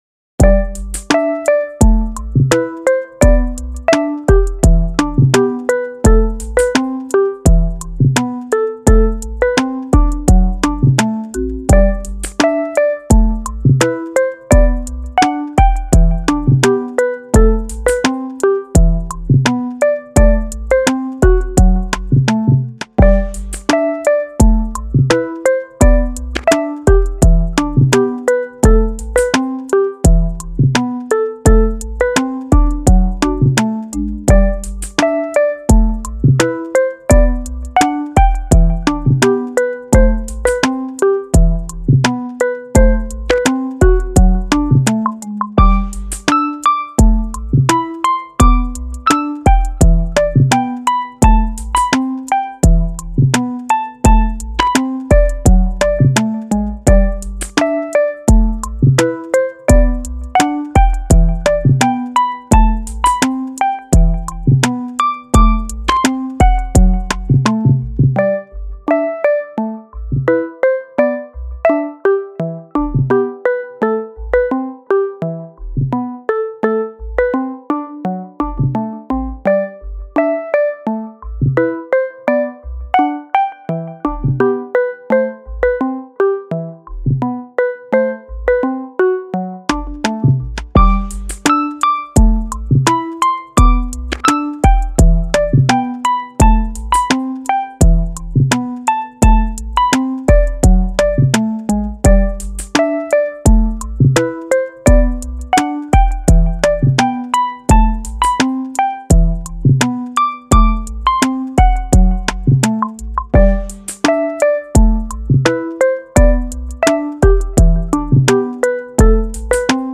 フリーBGM > チル・穏やか
明るい・ポップ